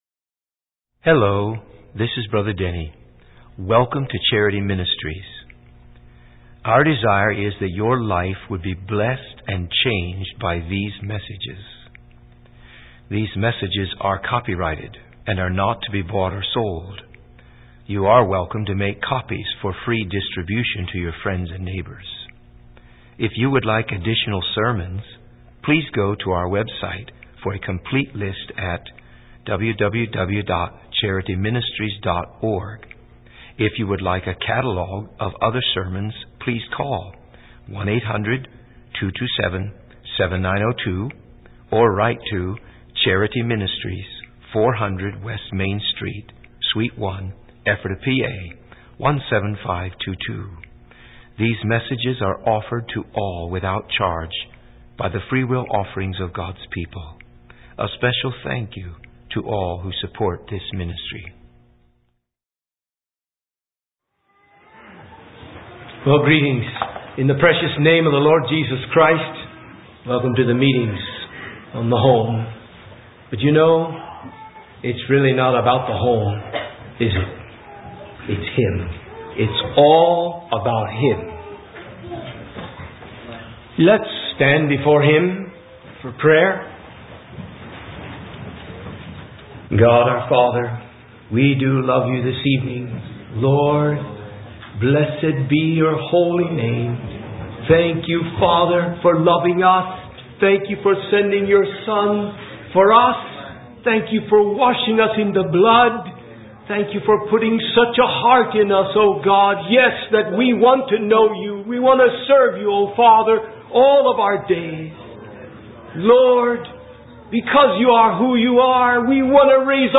preached at Charity Christian Fellowship.